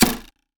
ArrowPenetration_Wood 02.wav